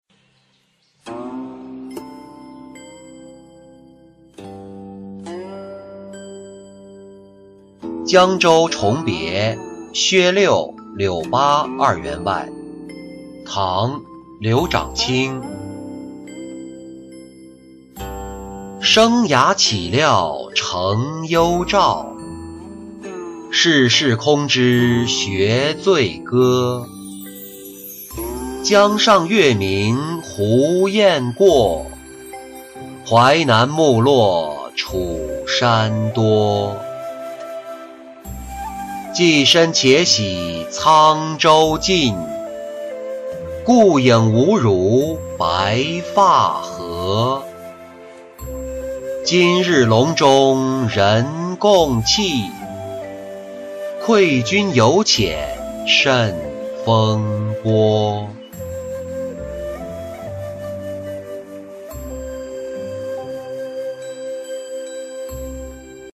江州重别薛六柳八二员外-音频朗读